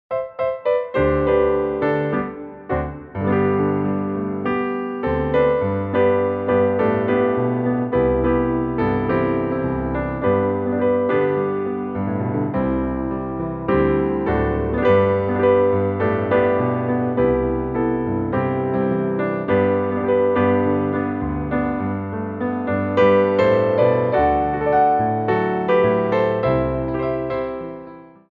Tendus with Pirouette
4/4 (8x8)